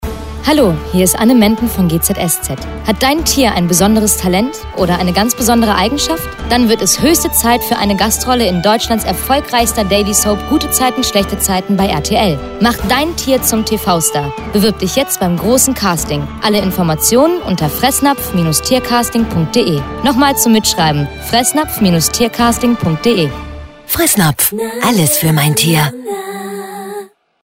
Anne Menden für Fressnapf – Hörfunk